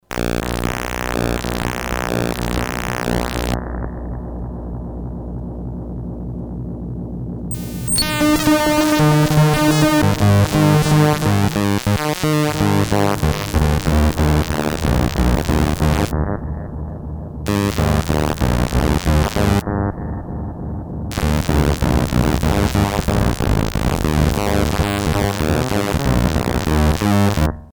Analog Delay (Evans/Aria)
The Locobox is quite nice and warm, but sound really muffled even with full open filter.
full feedback